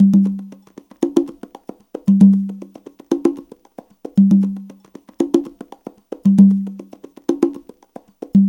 CONGA BEAT22.wav